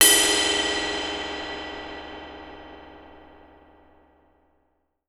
17 WIND GONG.wav